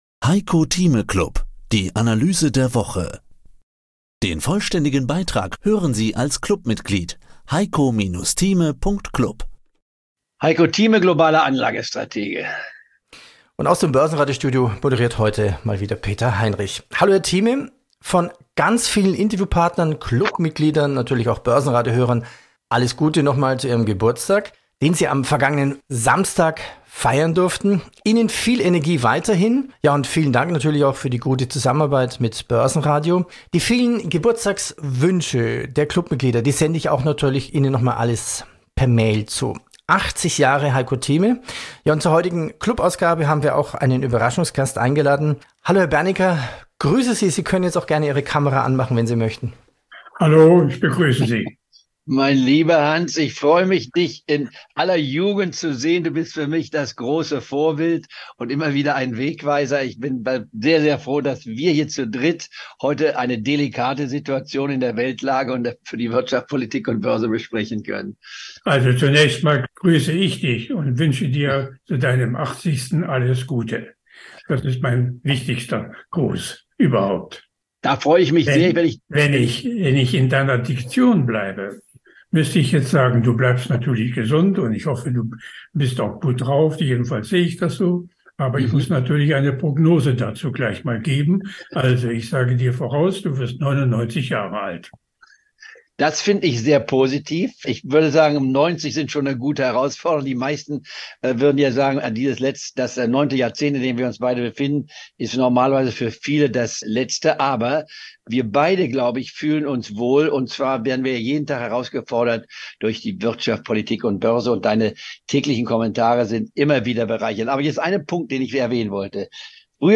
Im Heiko Thieme Club hören Sie ein mal wöchentlich ein exklusives Interview zum aktuellen Börsengeschehen, Einschätzung der Marktlage, Erklärungen wie die Börse funktioniert oder Analysen zu einzelnen Aktienwerten.
Als Überraschungsgast schaute Hans Bernecker in diese Clubausgabe herein. 165 Jahre Lebenserfahrung und über 125 Jahre geballte Börsenerfahrung in einem Podcast. Warum hält Hans Bernecker nichts von der VW-Aktie?